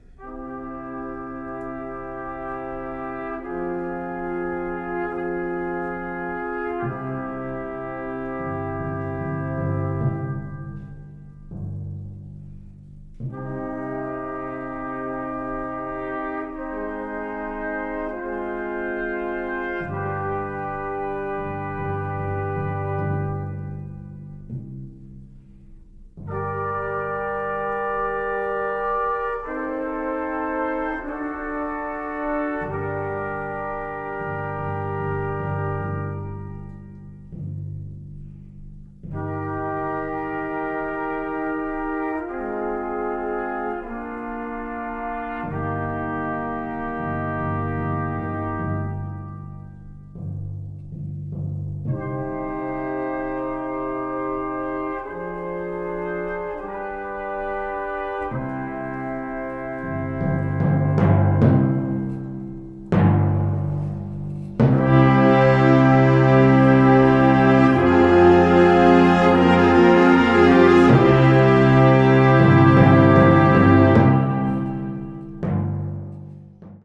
Konzertmusik für Blasorchester
Besetzung: Blasorchester